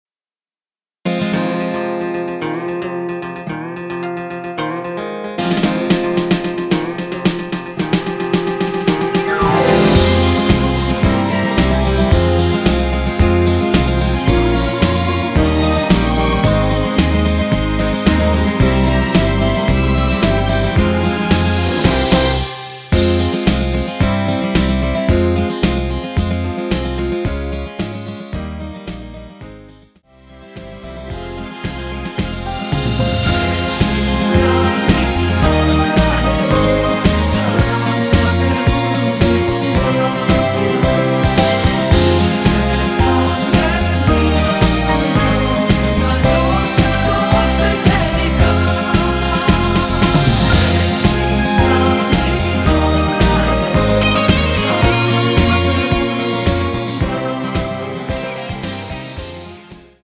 원키 코러스 포함된 MR 입니다.(미리듣기 참조)
Ab
앞부분30초, 뒷부분30초씩 편집해서 올려 드리고 있습니다.